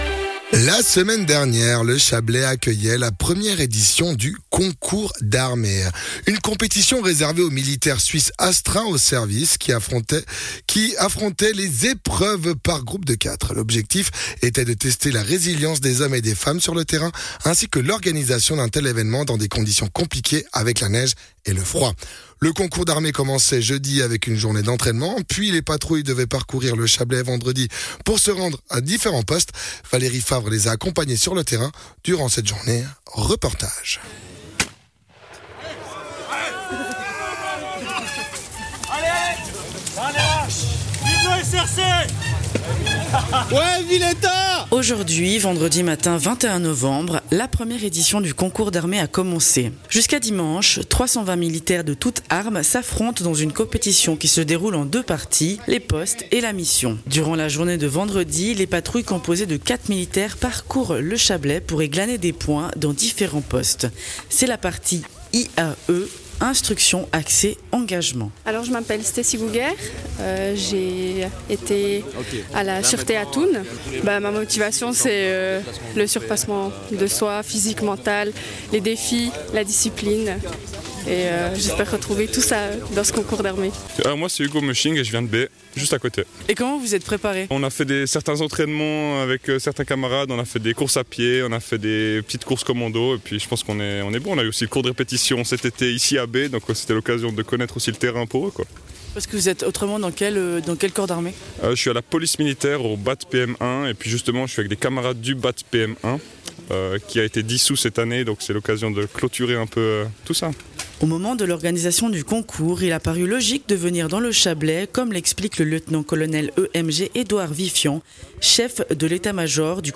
Reportage au cœur de la première édition du Concours d'armée